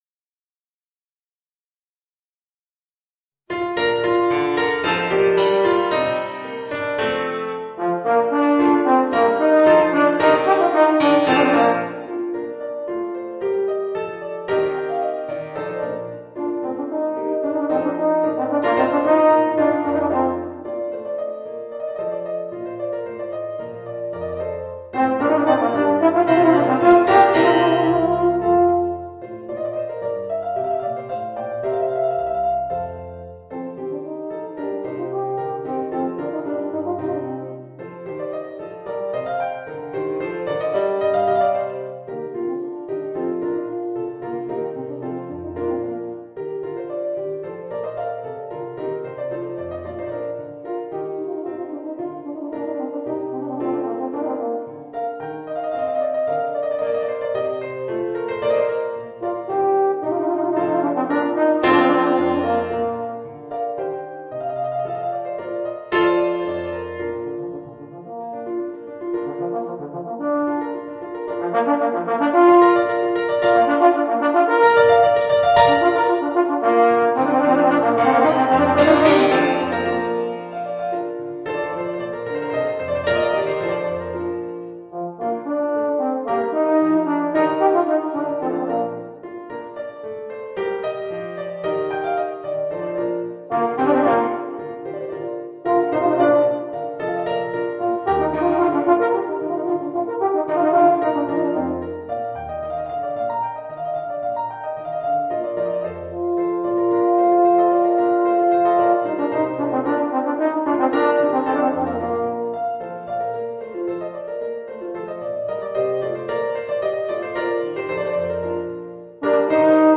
Voicing: Euphonium and Organ